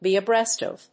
アクセントbe [kèep] abréast of [with]…